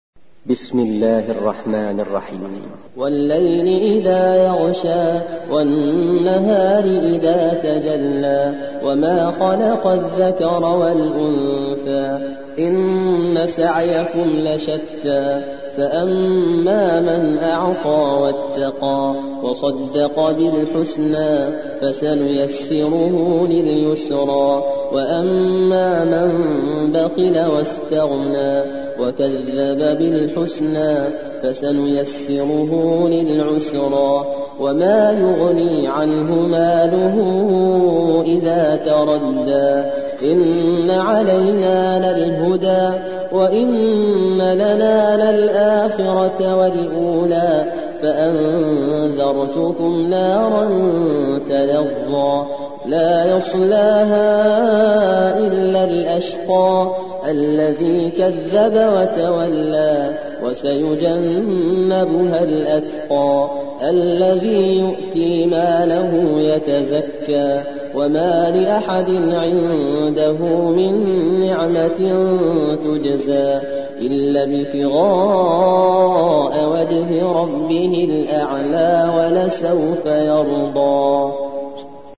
92. سورة الليل / القارئ